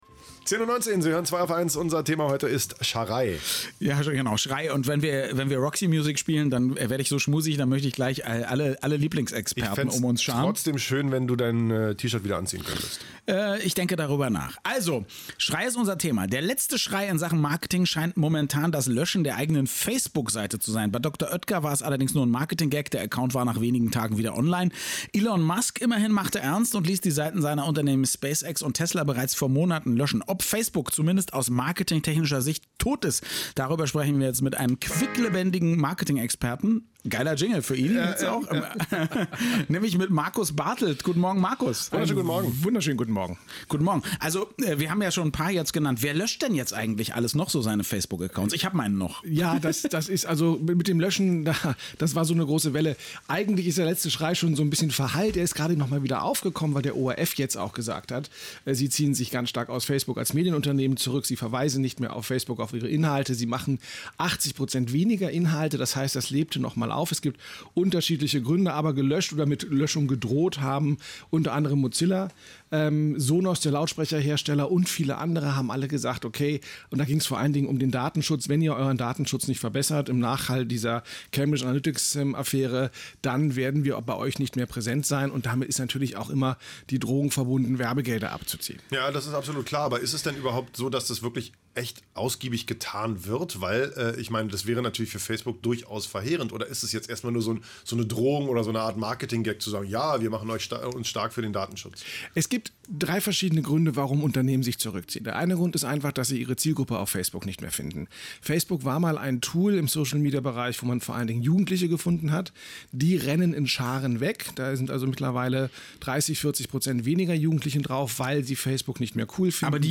Die Sommerpause ist vorbei, „Zwei auf eins“ sendet wieder und zum heutigen Thema „Schrei“ wurde ich wieder ins radioeins-Studio eingeladen, um zu berichten, was gerade im Marketing der letzte Schrei ist: